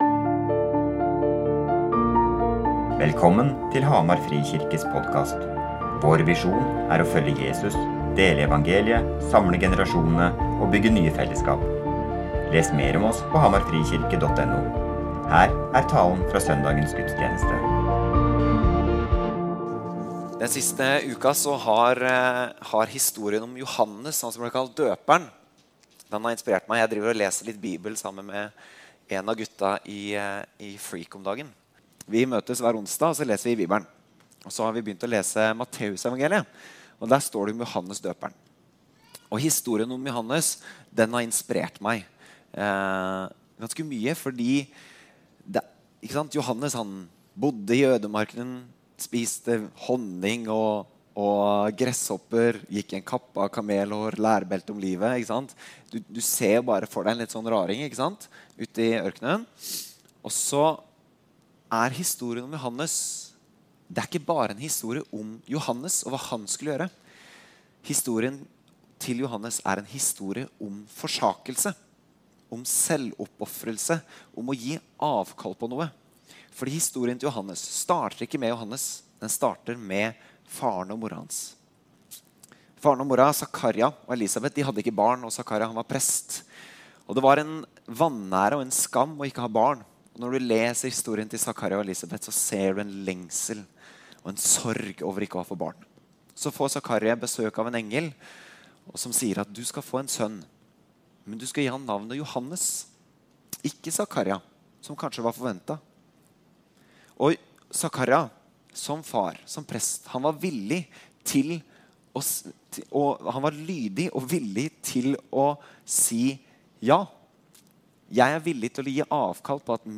Talen er en del av taleserien vår Snakk om det!